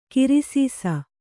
♪ kirisīsa